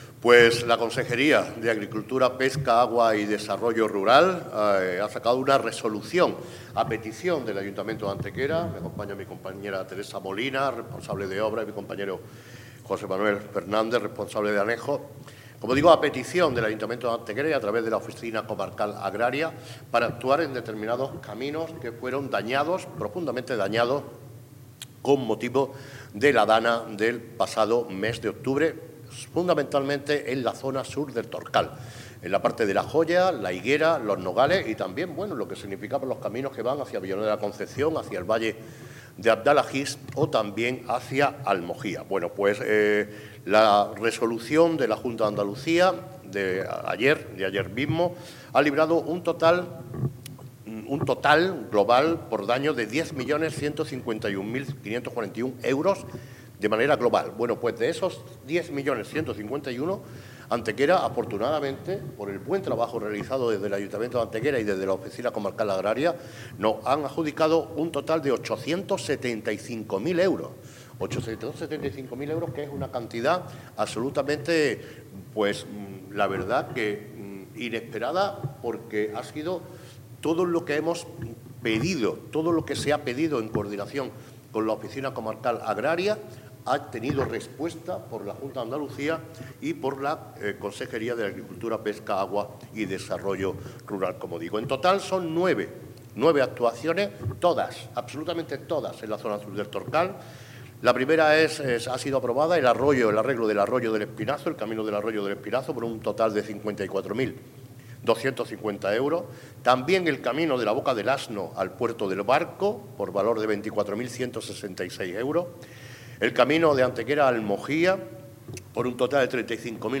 El alcalde de Antequera, Manolo Barón, informa de la resolución que ha emitido la Consejería de Agricultura, Pesca, Agua y Desarrollo Rural declarándose la tramitación de emergencia de la contratación de las obras contempladas en el Plan de Actuaciones de Emergencia para la Reparación de Caminos Rurales dañados por los fenómenos meteorológicos adversos acaecidos en nuestra comunidad autónoma entre el 29 de octubre y el 3 de noviembre.
Cortes de voz